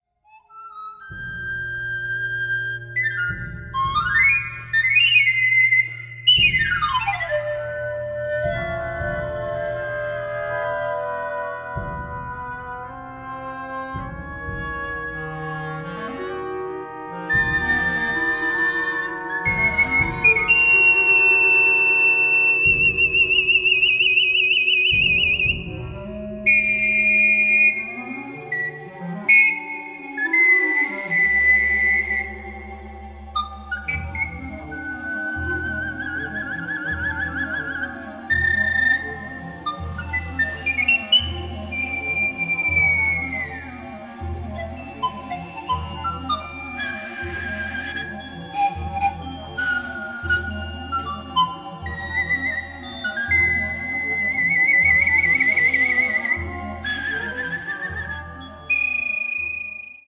Lieu: Salle de la Tuffière, Corpataux